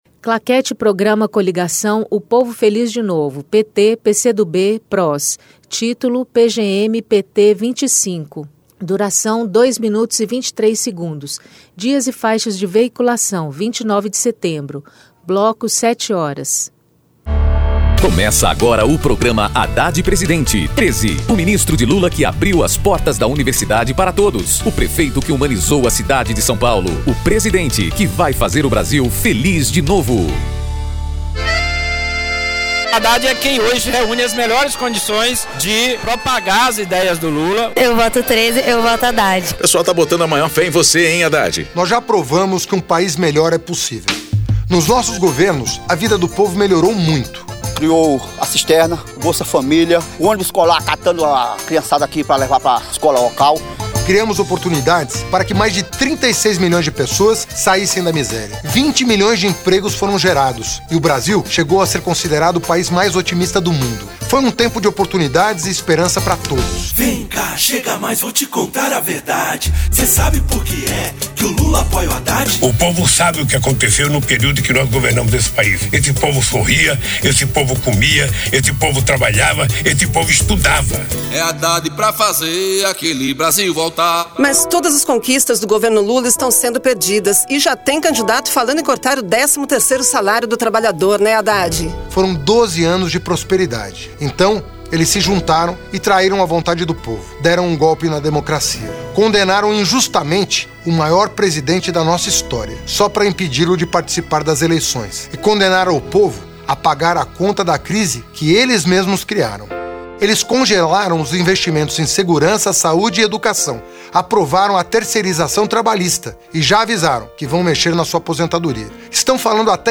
TítuloPrograma de rádio da campanha de 2018 (edição 25)
Descrição Programa de rádio da campanha de 2018 (edição 25) - 1° turno